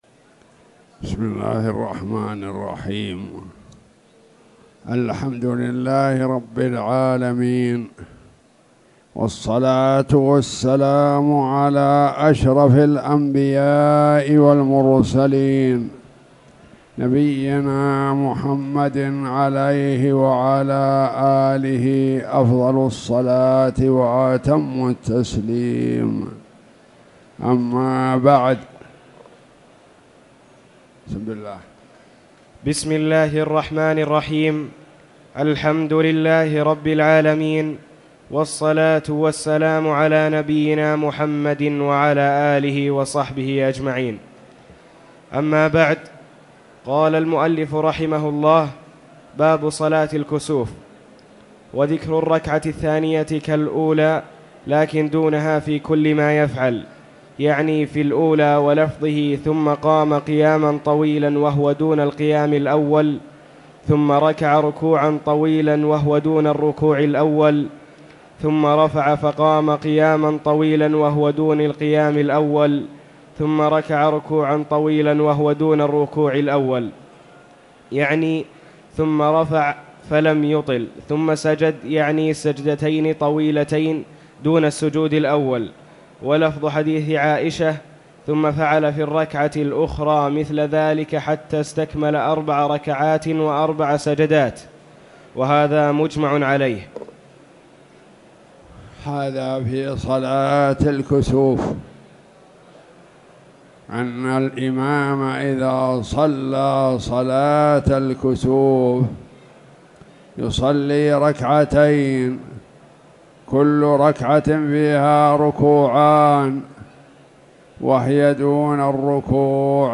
تاريخ النشر ٤ رجب ١٤٣٨ هـ المكان: المسجد الحرام الشيخ